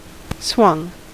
Ääntäminen
Vaihtoehtoiset kirjoitusmuodot (vanhahtava) swungen Ääntäminen US Tuntematon aksentti: IPA : /ˈswʌŋ/ Haettu sana löytyi näillä lähdekielillä: englanti Käännöksiä ei löytynyt valitulle kohdekielelle.